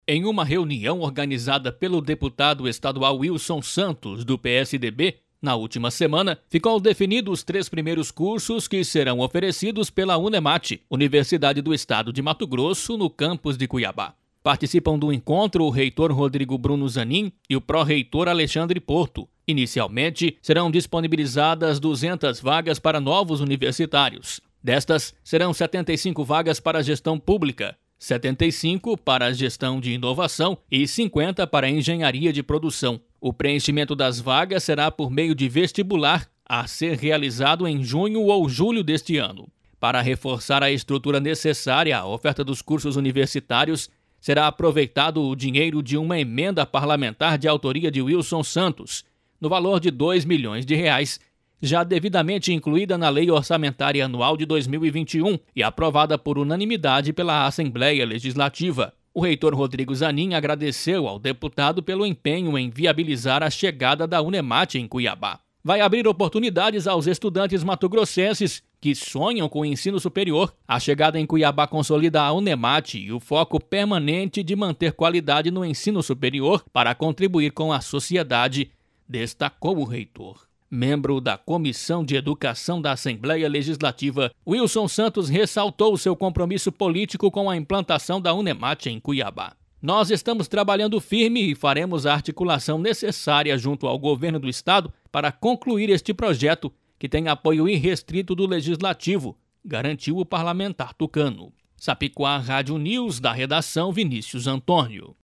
Boletins de MT 18 jan, 2021